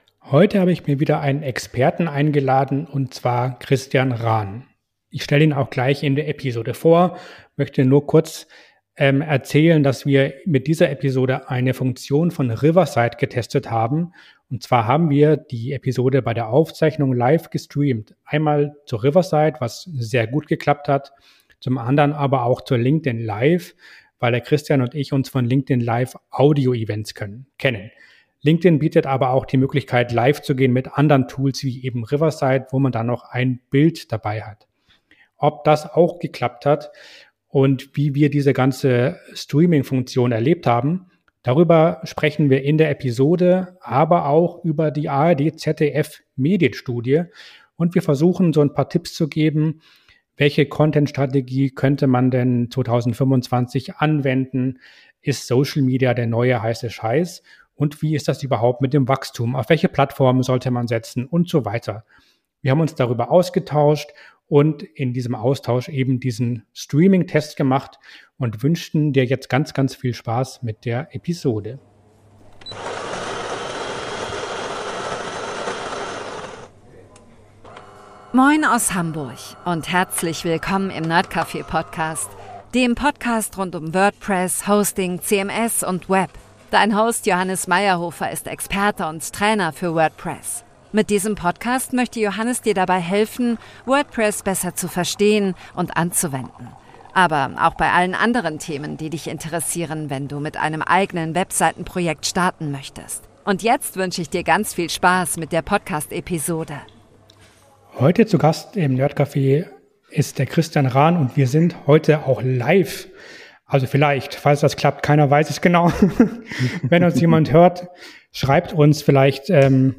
Diese Episode haben wir nicht nur aufgenommen, sondern auch live gestreamt.